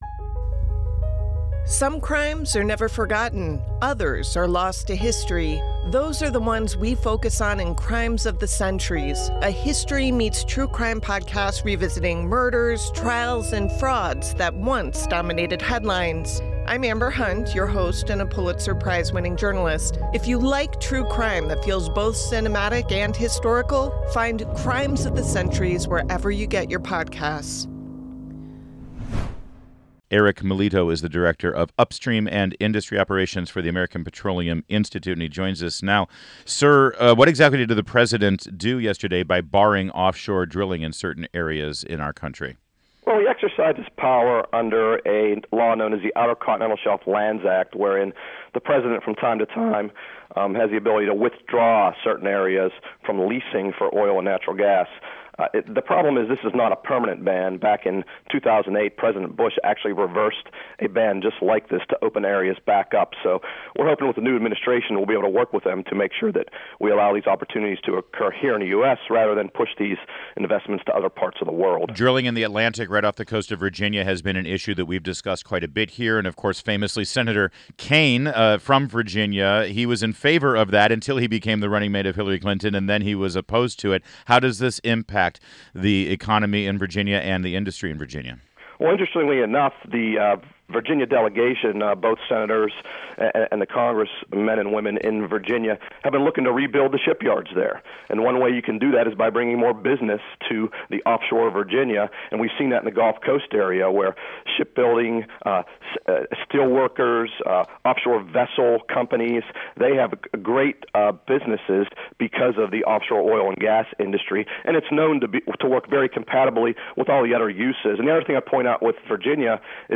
WMAL Interview
INTERVIEW